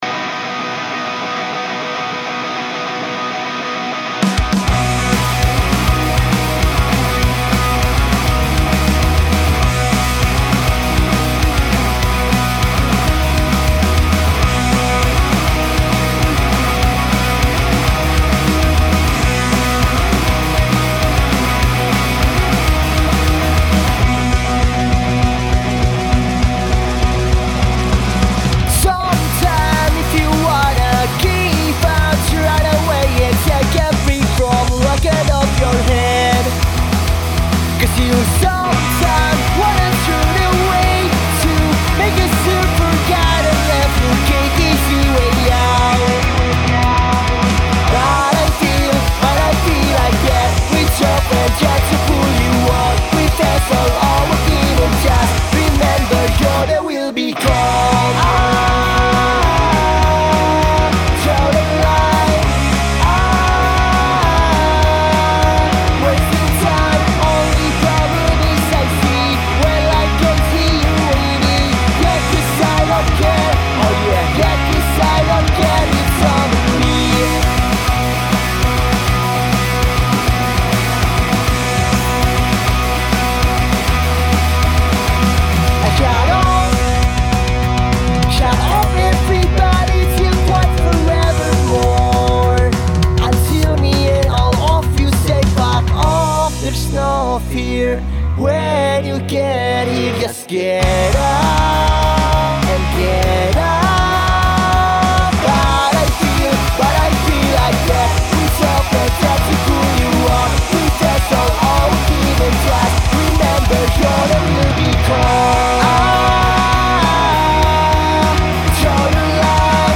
Banten Alternative